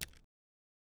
add keyboard clicking sounds
click.wav